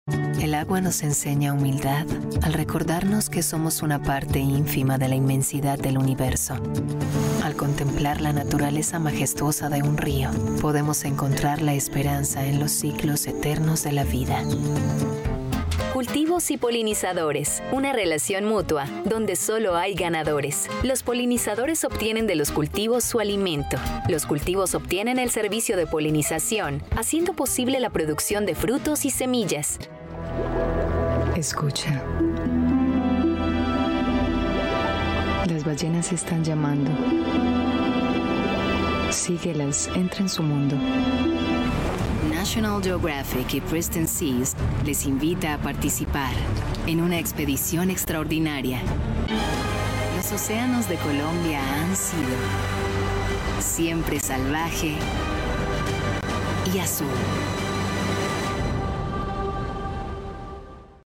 Espanhol (latino-americano)
Documentários
Cabine de gravação profissional isolada